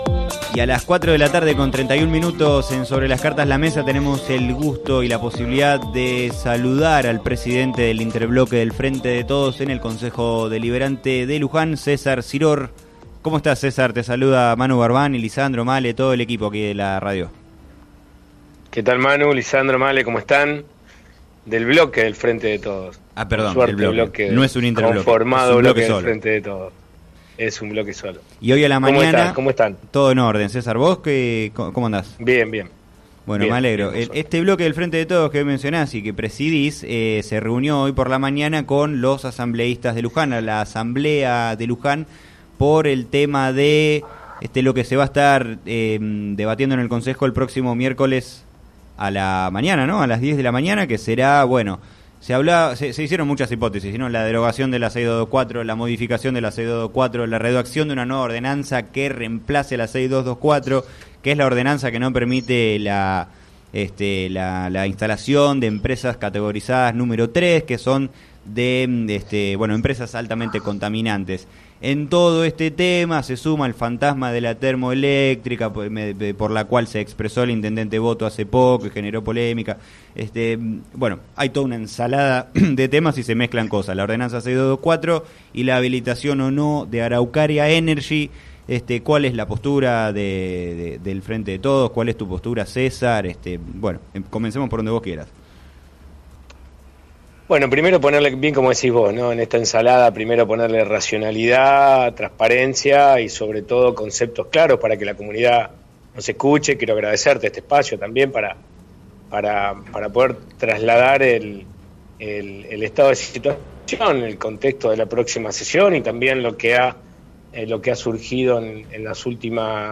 En declaraciones al programa “Sobre las cartas la mesa” de FM Líder 97.7, el jefe de los ediles oficialistas calificó de ilegal la instalación de la planta ubicada en el cruce de rutas 6 y 34 y fue enfático respecto de su posición.